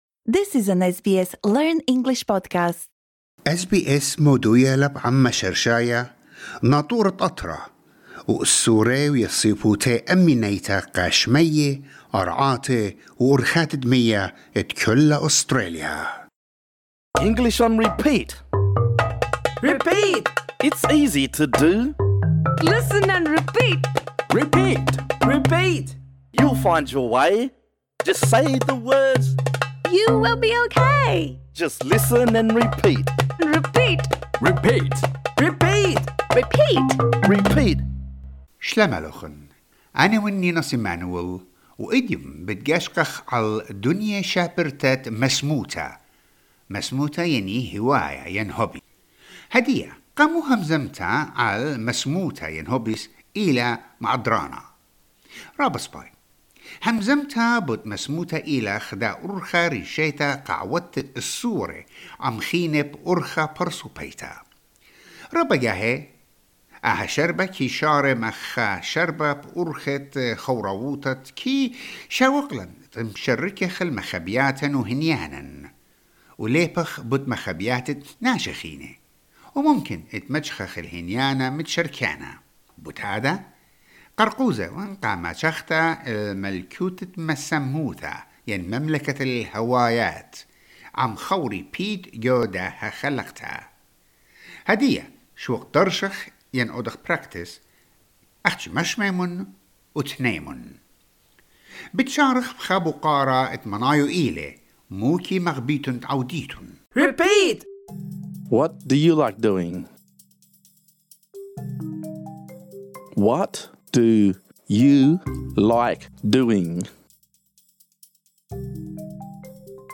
This lesson is designed for easy-level learners. In this episode, we practise saying the following phrases: What do you like doing?